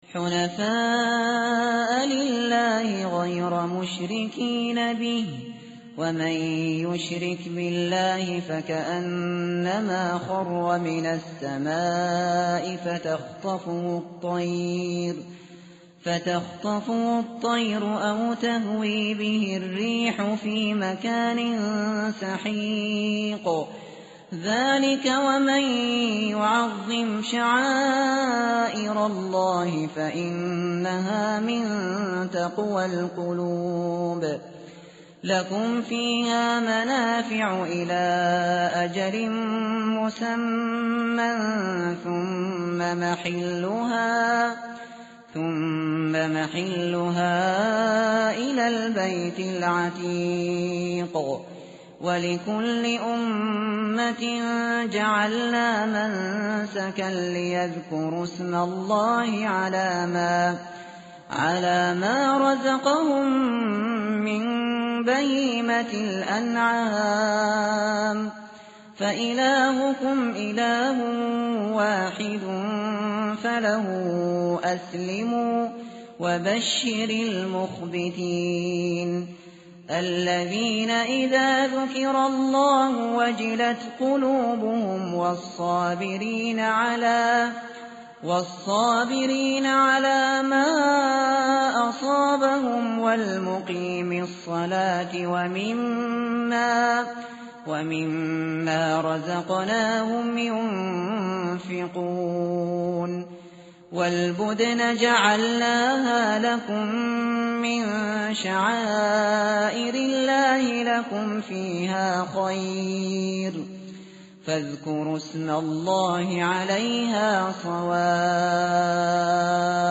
tartil_shateri_page_336.mp3